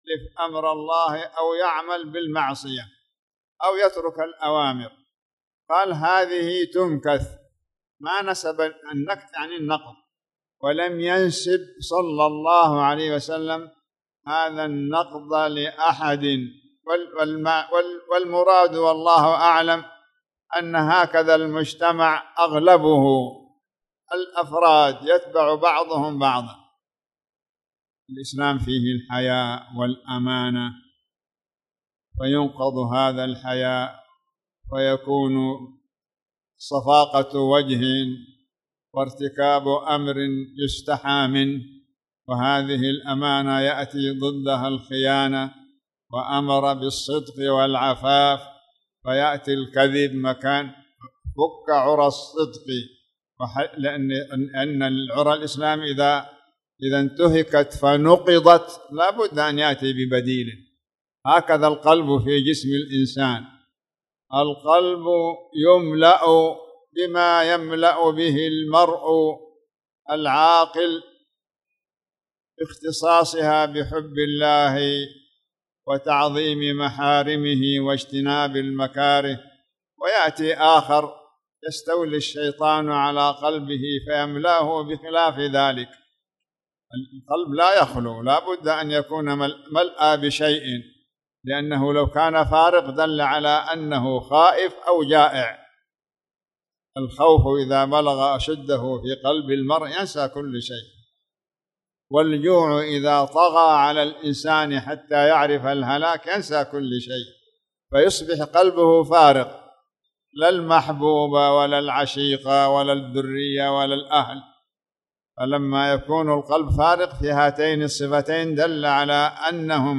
تاريخ النشر ٥ ربيع الثاني ١٤٣٨ هـ المكان: المسجد الحرام الشيخ